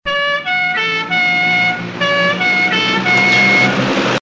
• OLD SIREN AND ACCELERATION.mp3
old_siren_and_acceleration_dg1.wav